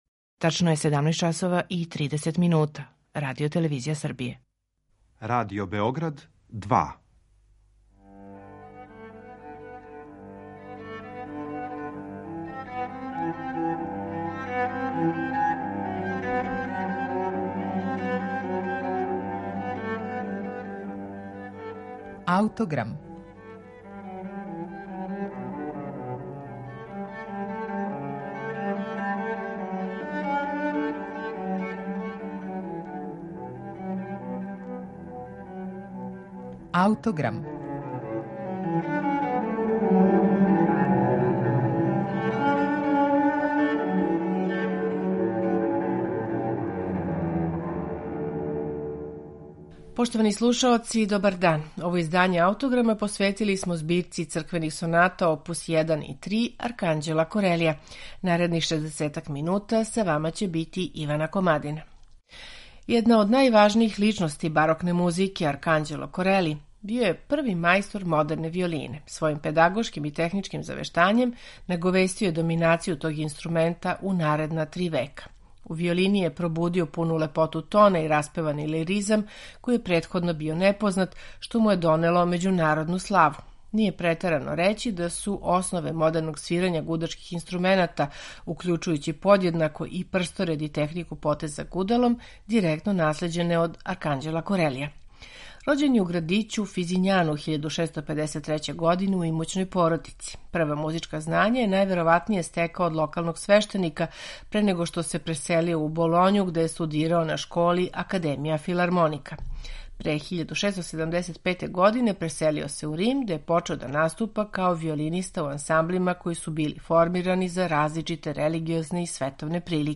Писане су као трио сонате - за две виолине и басо континуо. Од камерних соната већ на први поглед разликују се по томе што су овде у континуо укључене оргуље. Структура ставова им је комплекснија, као и употребљена контрапунктска техника.
виолончело
бас-лаута